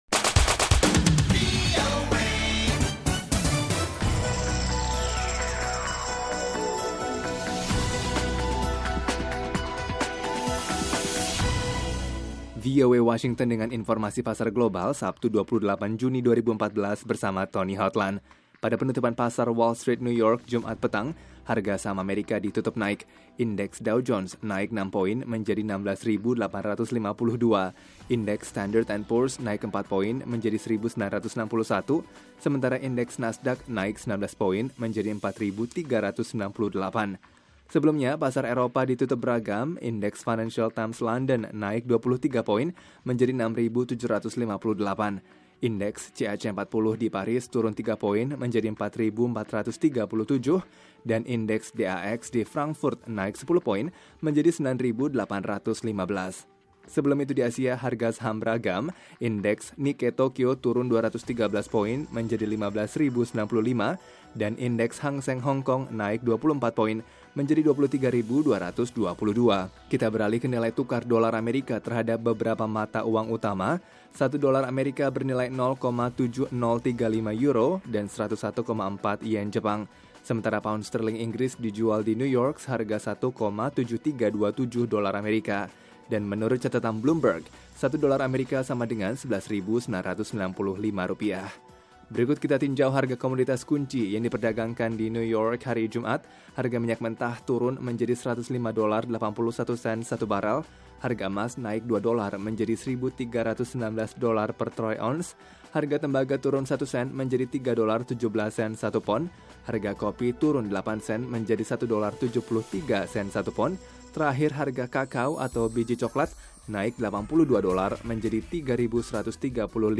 berita terkini